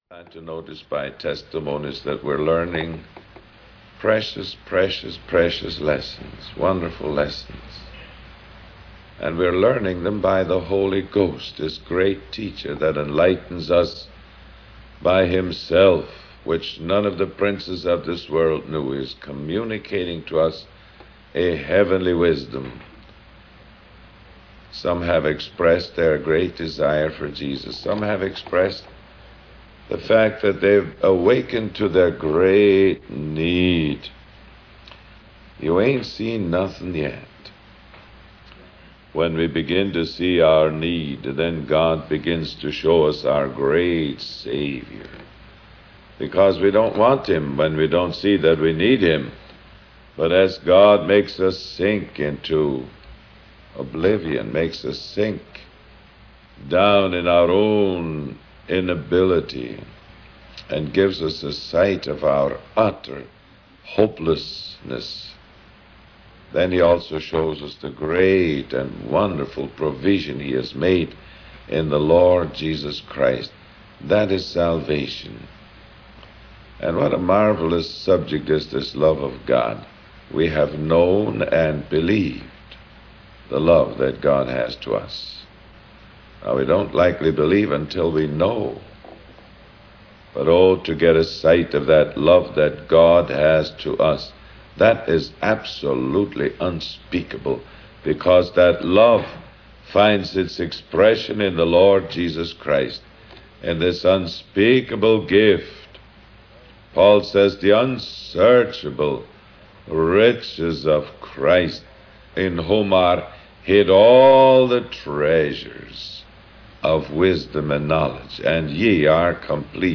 In this sermon, the preacher emphasizes the power of God's love and how it affects every aspect of our lives.